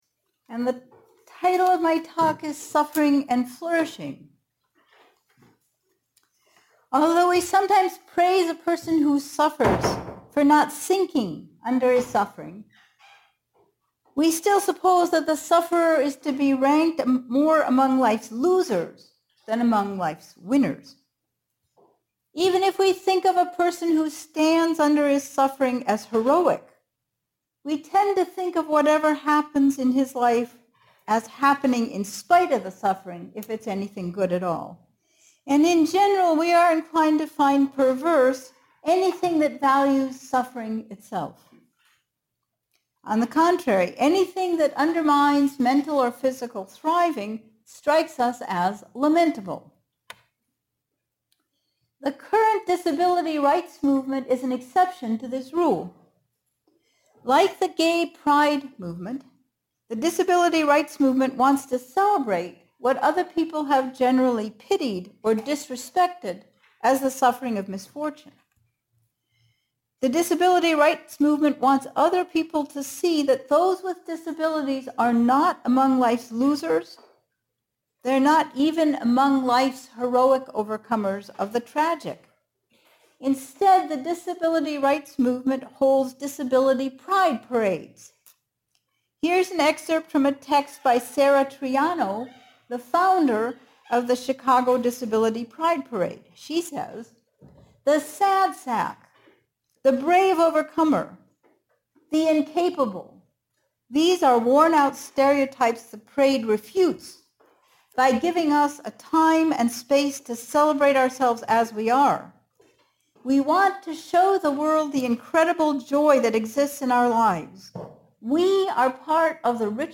This lecture was given at University College Dublin on 8 October 2019.